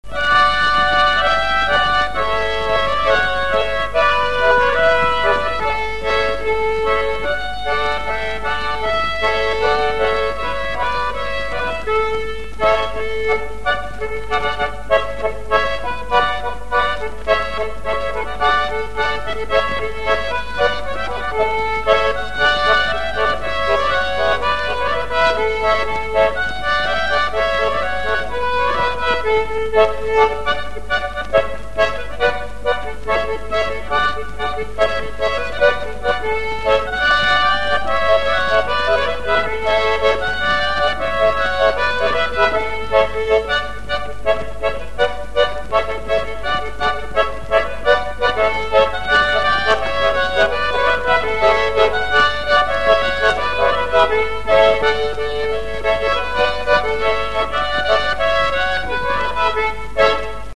Необычная ливенка